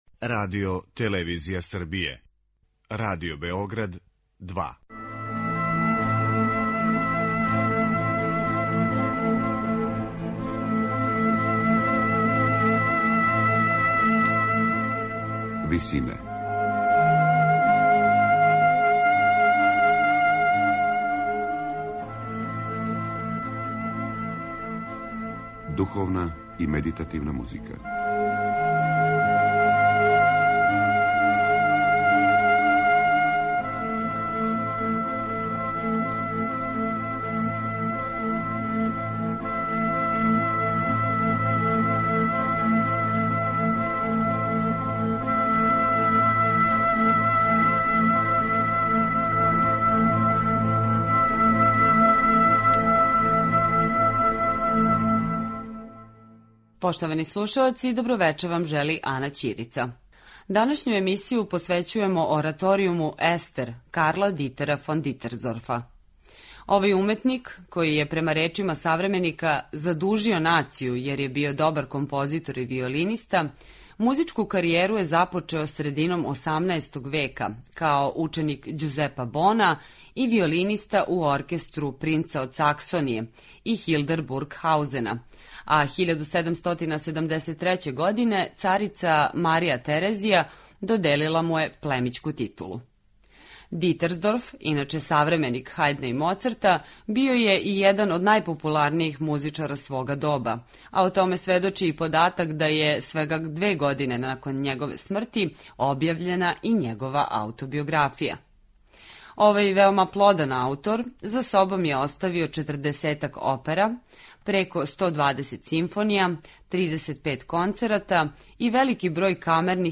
Ораторијум „Естер”